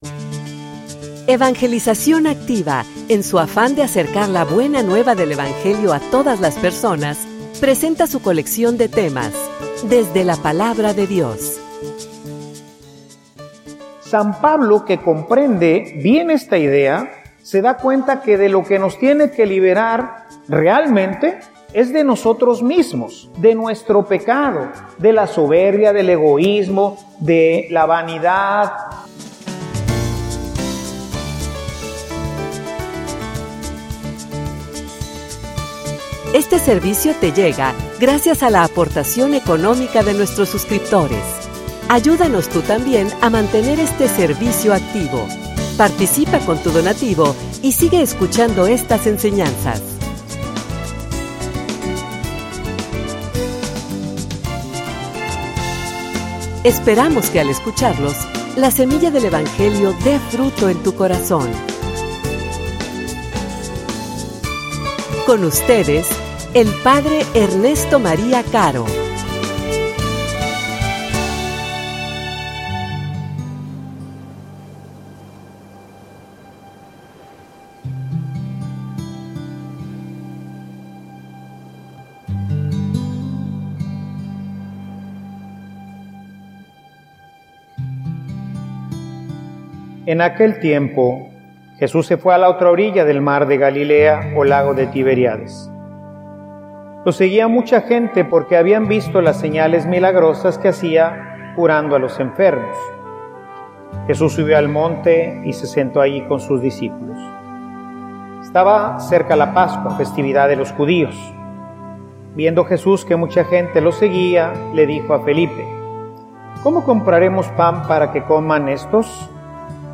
homilia_Tu_por_que_sigues_a_Jesus..mp3